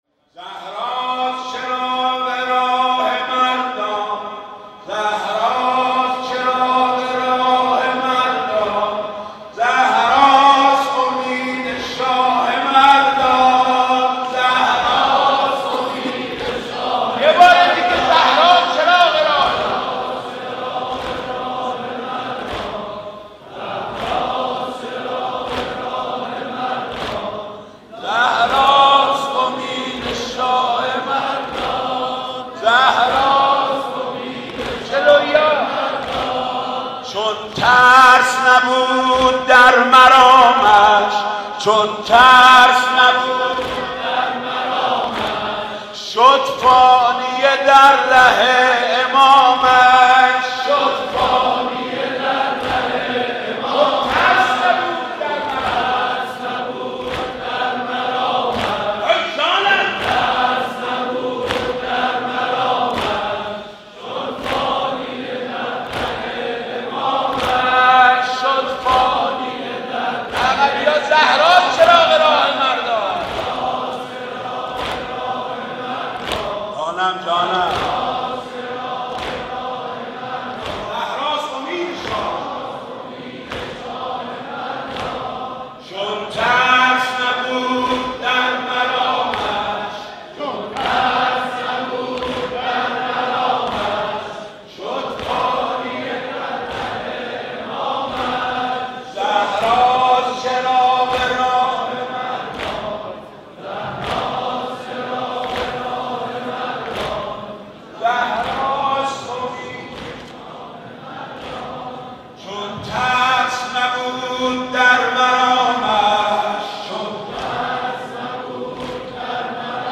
مناسبت : شهادت حضرت فاطمه زهرا سلام‌الله‌علیها
قالب : زمینه